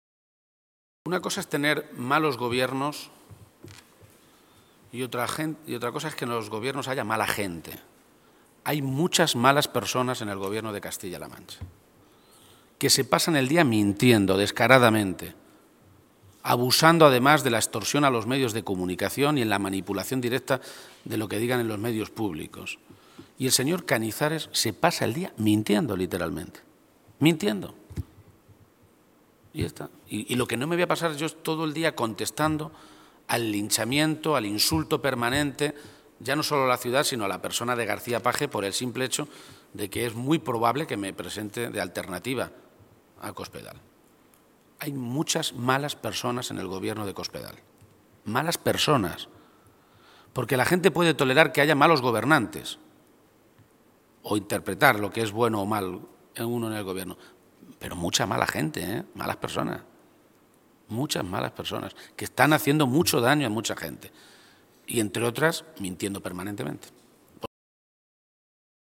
García-Page se pronunciaba de esta manera esta mañana, en Toledo, a preguntas de los medios de comunicación, y añadía que “ahora resulta que cuando el Gobierno de Cospedal llama a la UGT para firmar un acuerdo de salud laboral, la UGT es buena. Pero si la UGT recurre el Plan de Recursos Humanos de Cospedal para el futuro Hospital de Toledo, es Page el que está detrás”.
Cortes de audio de la rueda de prensa